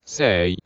swordman_select3.wav